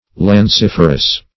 Lanciferous \Lan*cif"er*ous\, a.